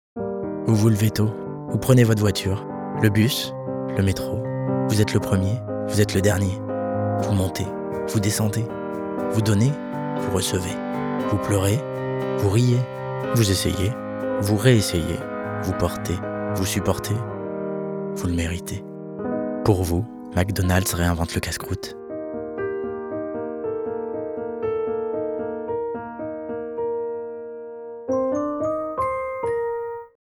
Voix Off McDonalds